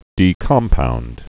(dē-kŏmpound, dēkəm-pound)